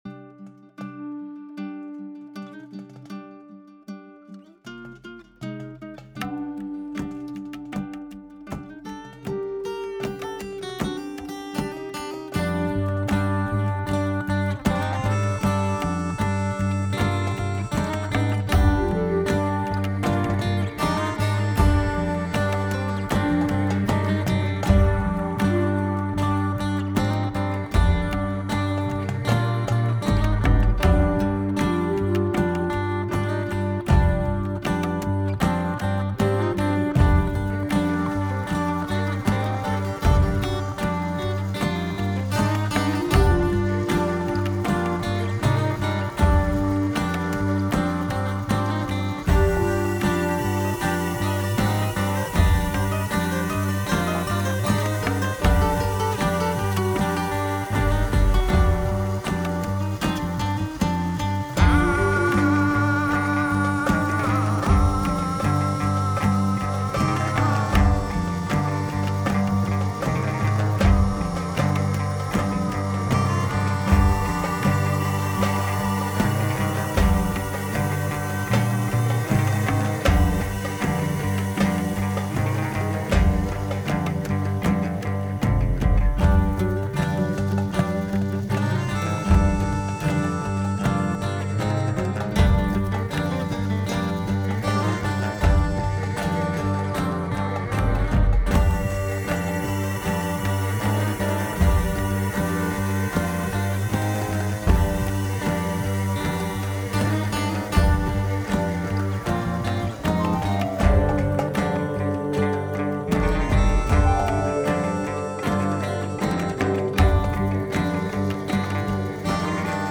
guitare/bass, tabla, mélodica et flûte Sard
puis une deuxième partie guitare enregistré à part avec un autre guitariste pour les parties solo quand la structure du morceau fût terminé...